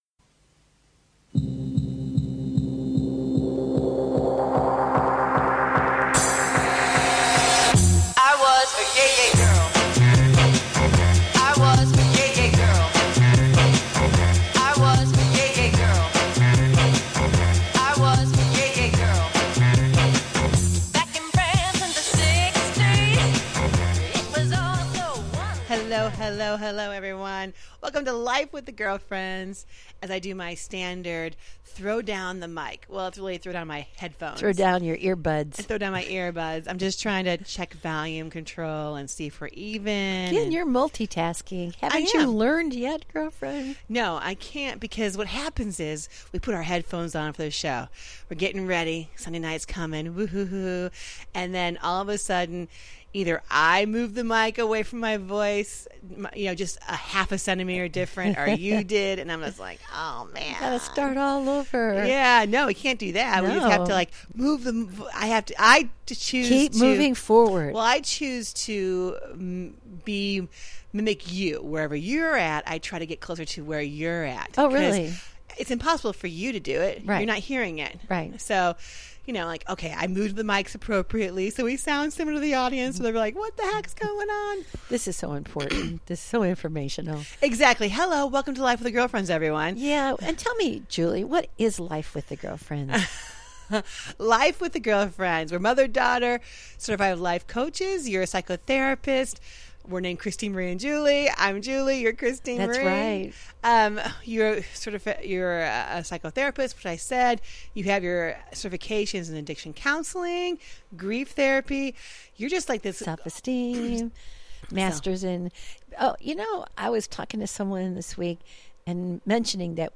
They welcome a wide range of guest to their den for some juicy conversation.
And join the girlfriends up close and personal for some daily chat that’s humorous, wholesome, and heartfelt.